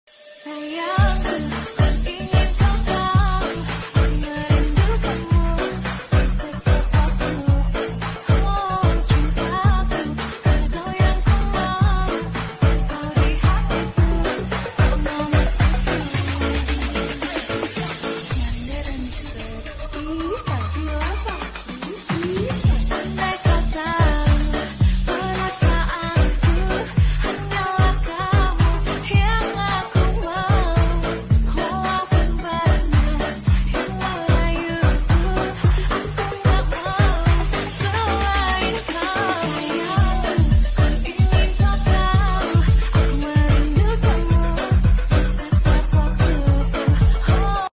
Live Streaming Sedekah Bumi Ds.